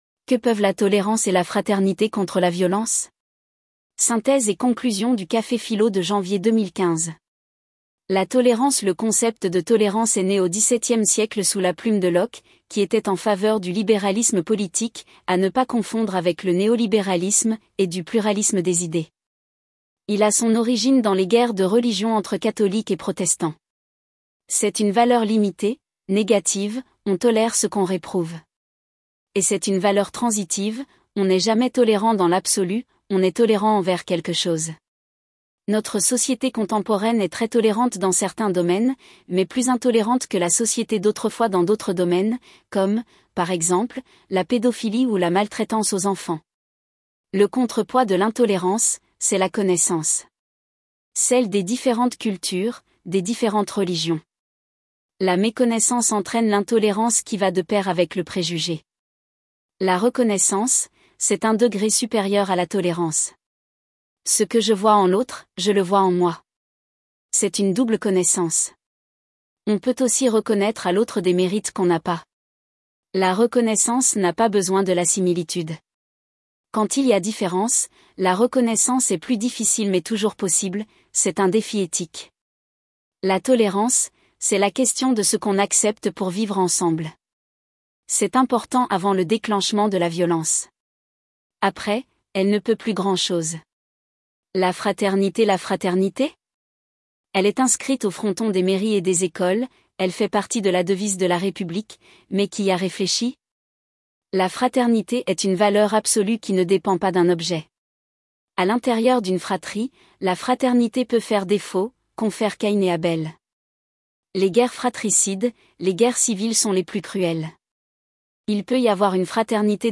Conférences et cafés-philo, Orléans
CAFÉ-PHILO PHILOMANIA Que peuvent la tolérance et la fraternité contre la violence ?